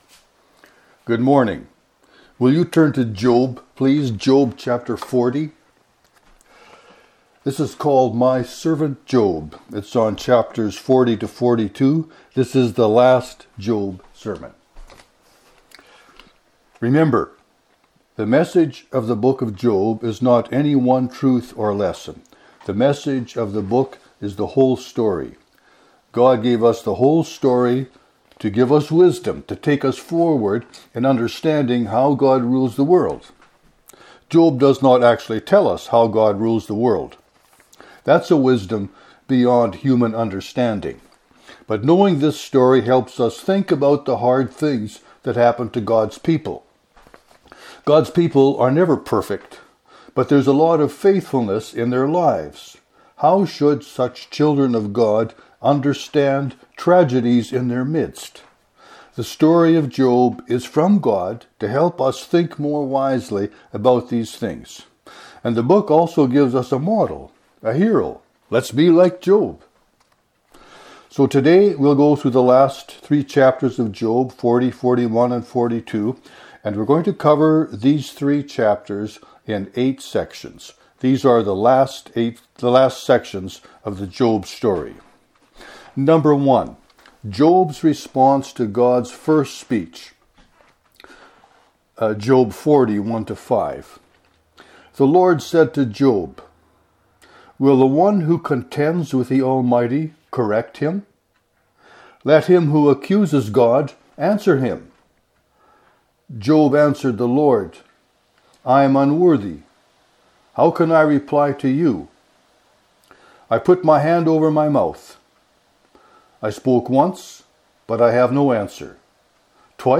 This the last Job sermon.